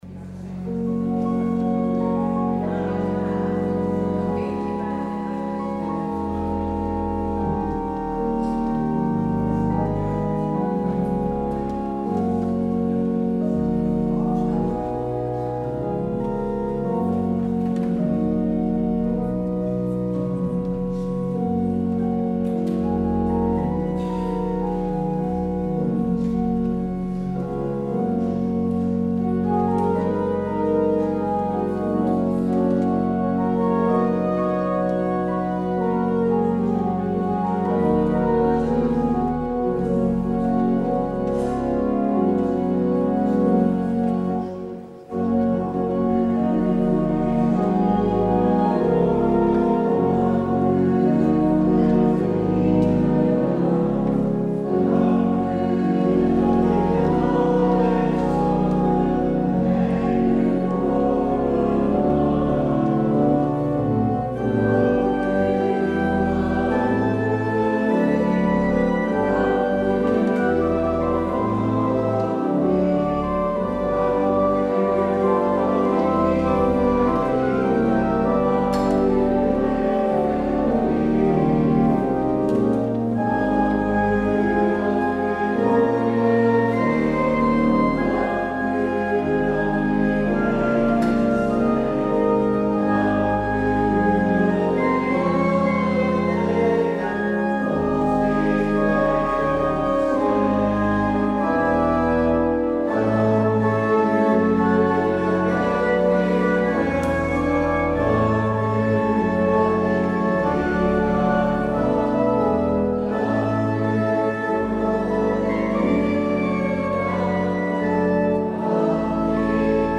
De voorganger vandaag is drs. Andries Knevel, theoloog uit Huizen.